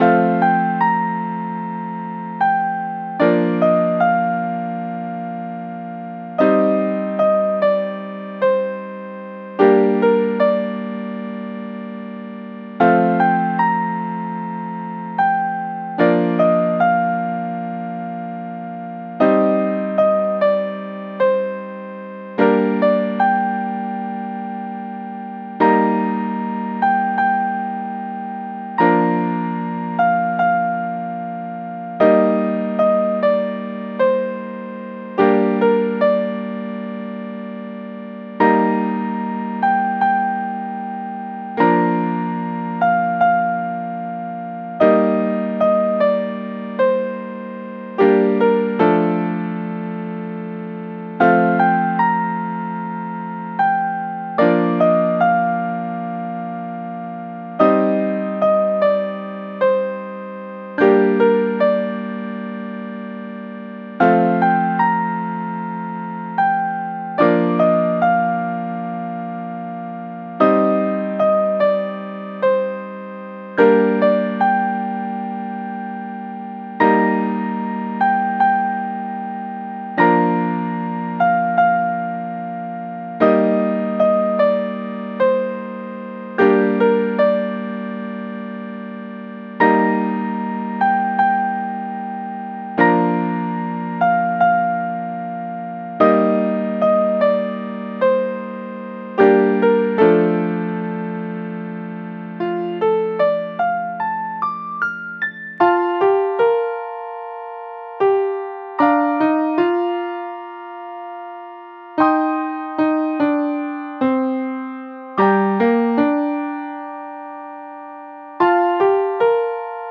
BPM：75 キー：A# ジャンル：おしゃれ、ゆったり 楽器：ピアノ